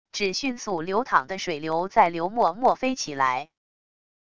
只迅速流淌的水流在流墨墨飞起来wav音频